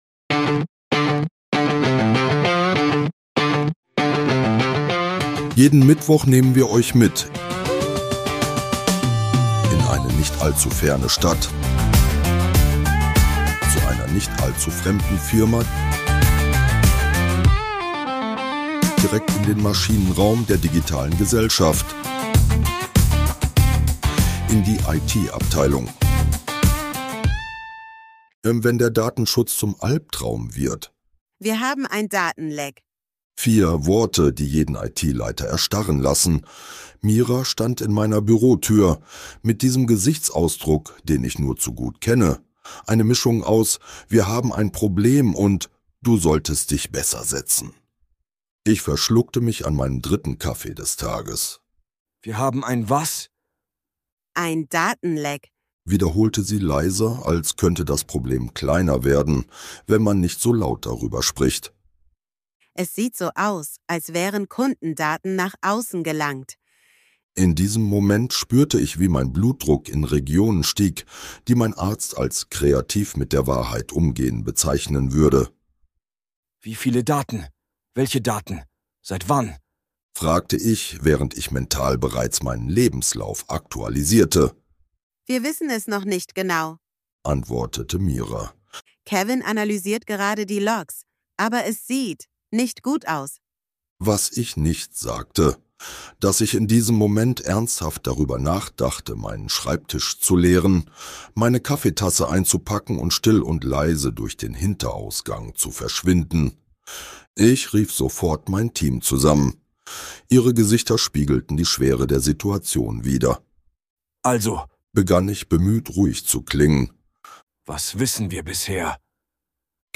Dieser Podcast ist Comedy.